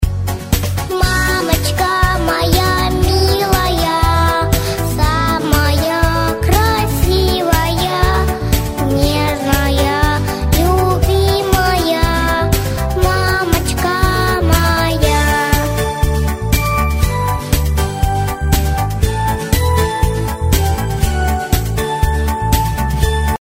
Рингтоны на маму
Детский голос